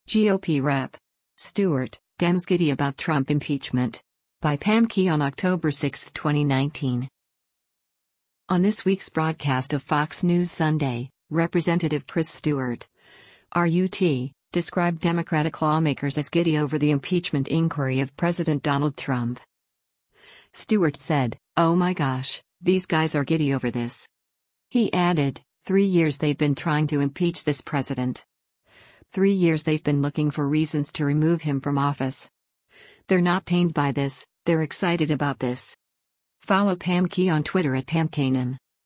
On this week’s broadcast of “Fox News Sunday,” Rep. Chris Stewart (R-UT) described Democratic lawmakers as “giddy” over the impeachment inquiry of President Donald Trump.